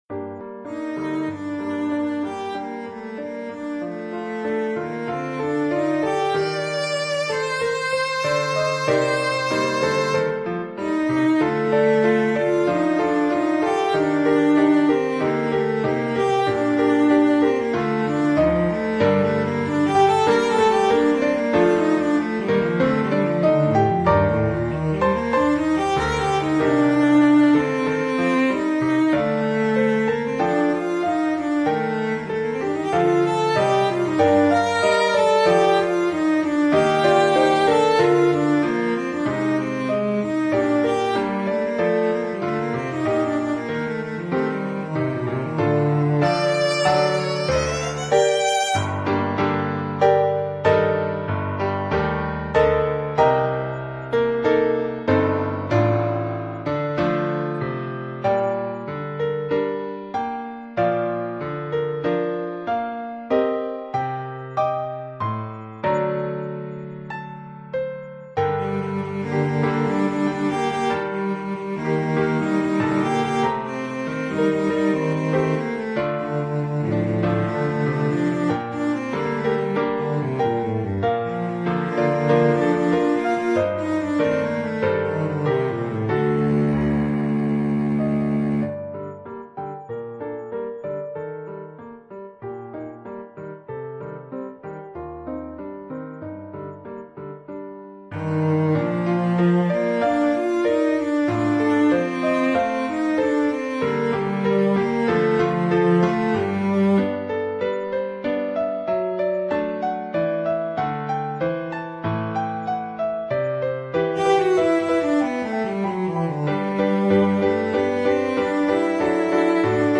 Formule instrumentale : Violoncelle et piano
Oeuvre pour violoncelle et piano.